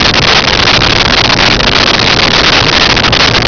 Sfx Ship Medium Loop
sfx_ship_medium_loop.wav